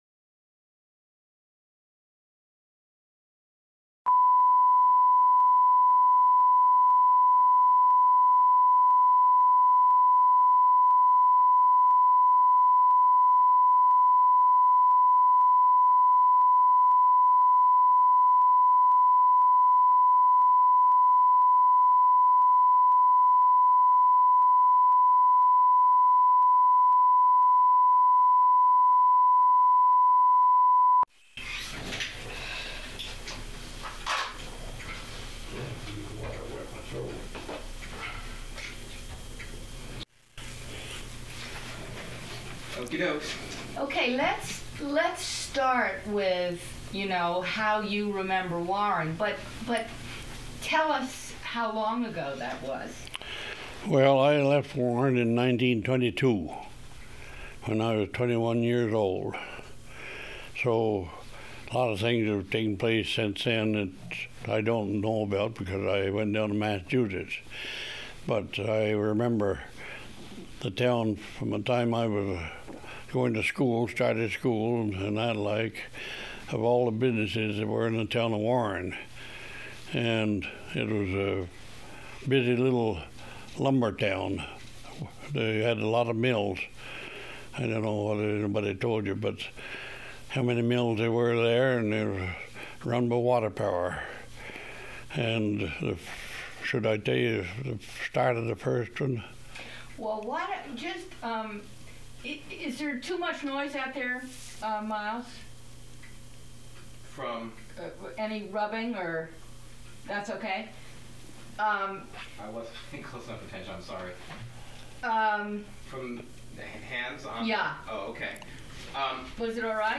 Interview
sound cassette (DAT)